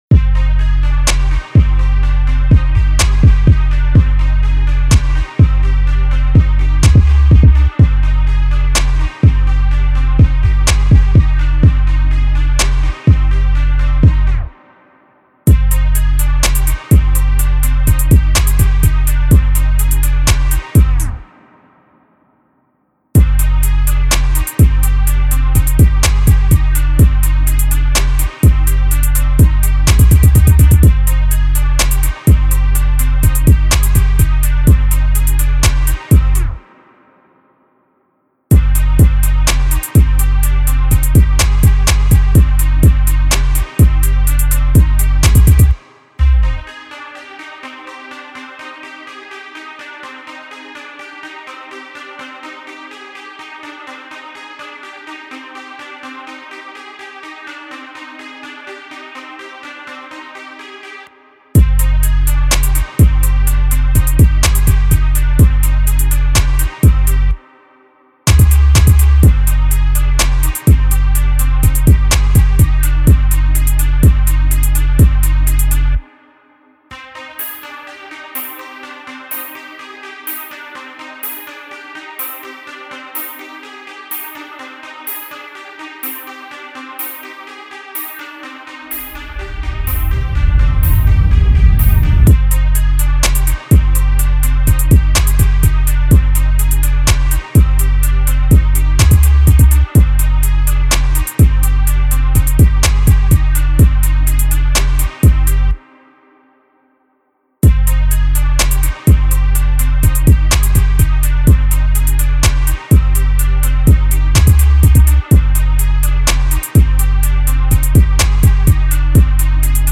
Trap Instrumentals